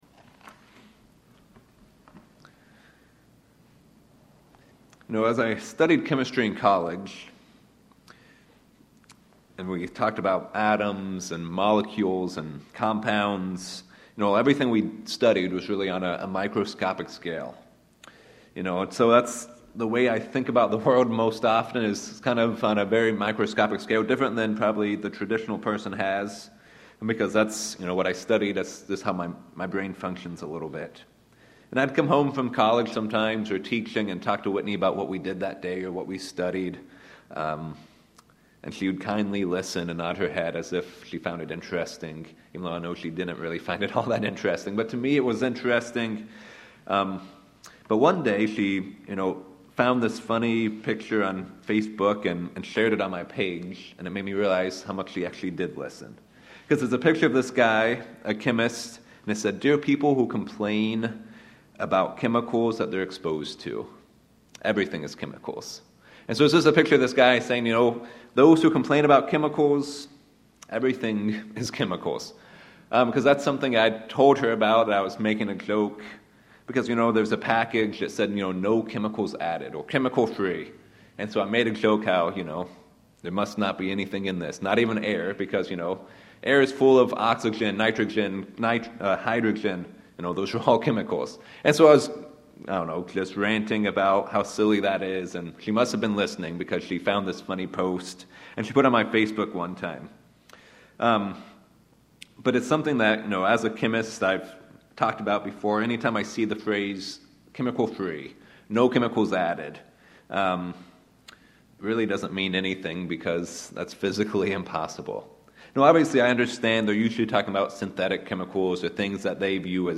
This sermon discusses three ways we can be deceived and how to defend against it.